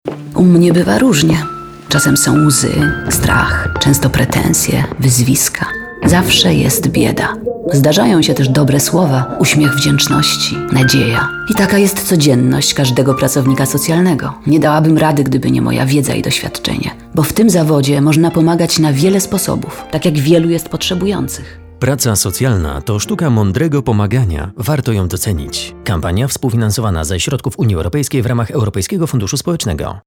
Spoty radiowe i telewizyjne